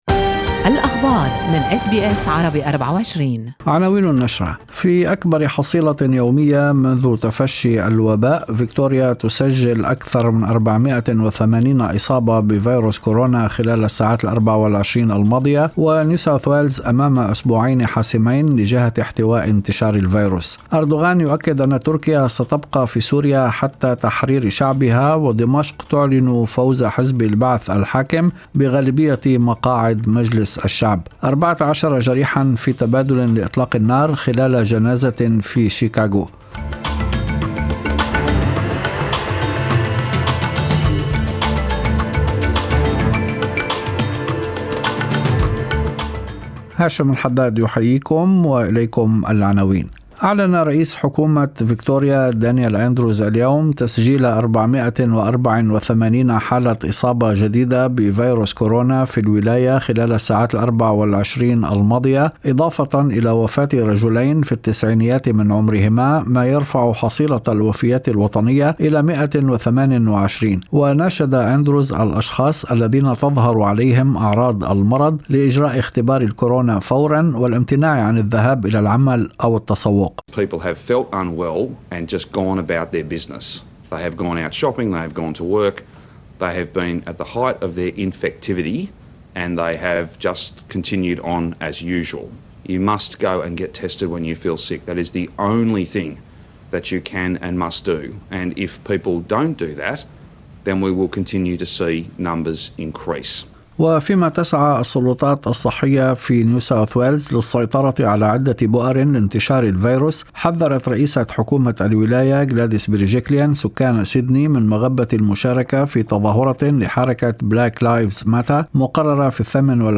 نشرة أخبار المساء 22/7/2020